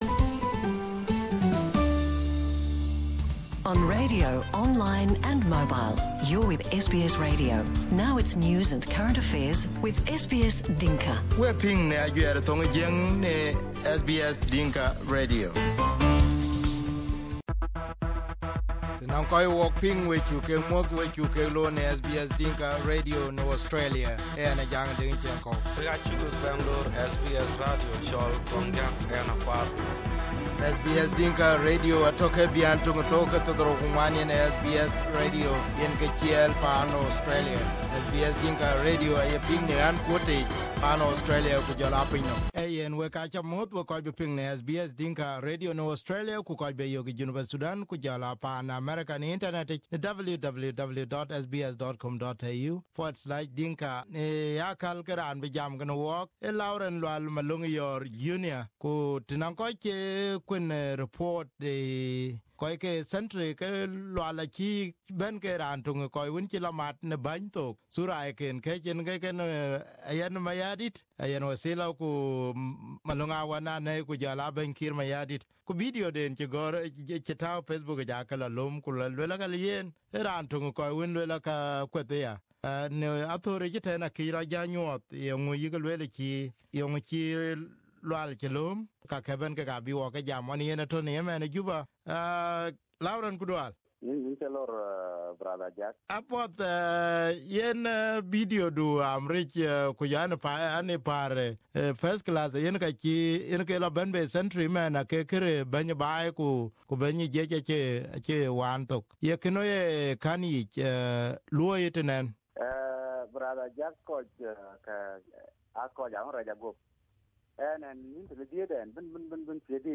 To help understand him, we conducted this interview on SBS Dinka Radio.